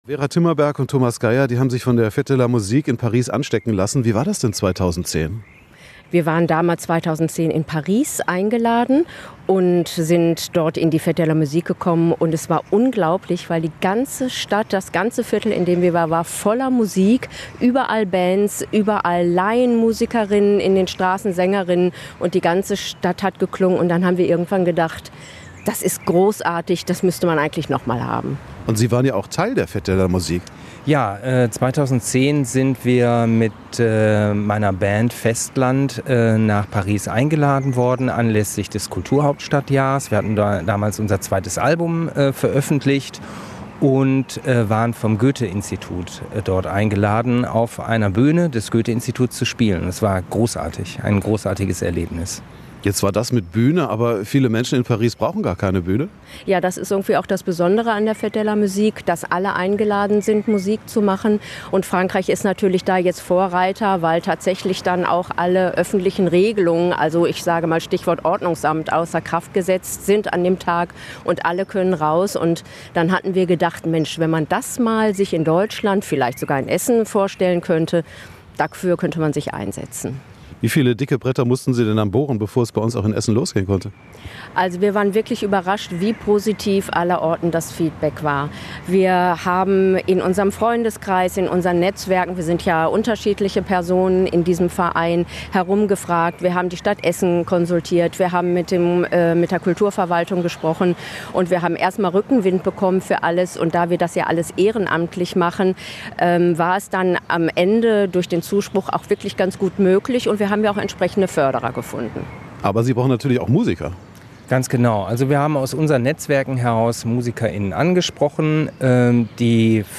hat die Organisatoren auf der Wiese hinter dem Museum Folkwang getroffen.